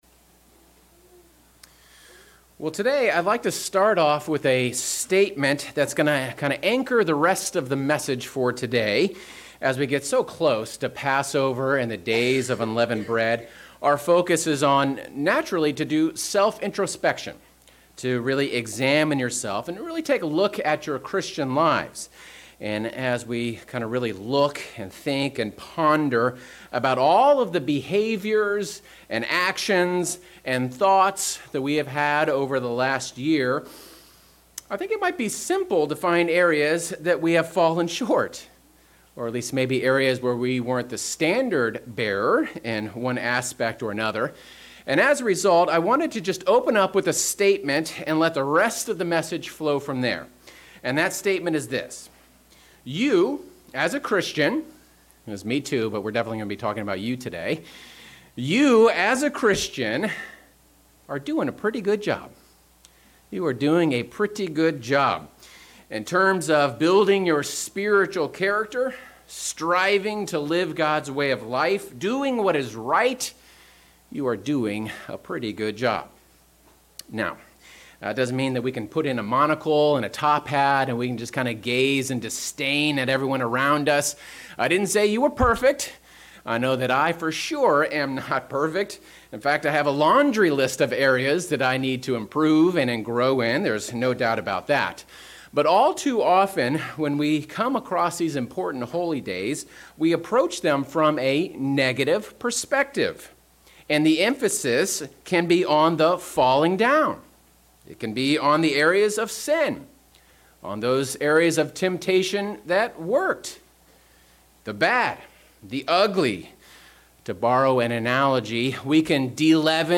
Given in Northern Virginia